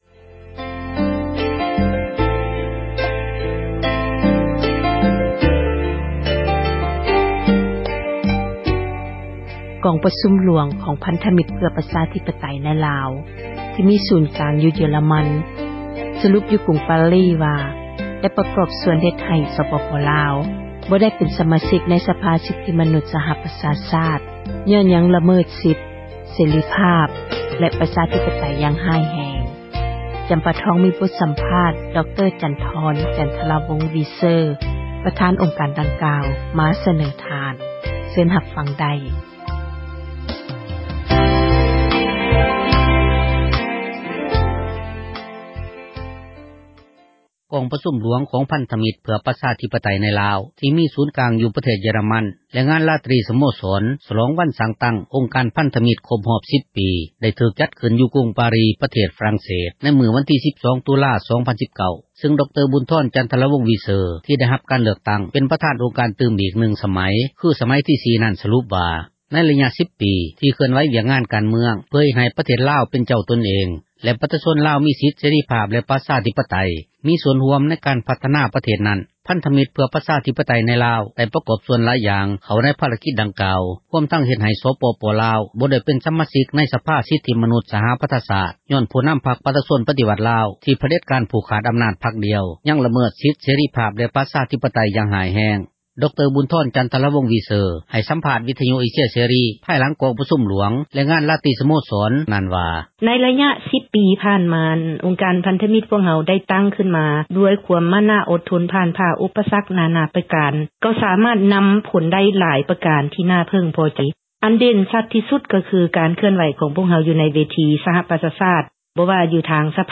(ສຽງສັມພາດ)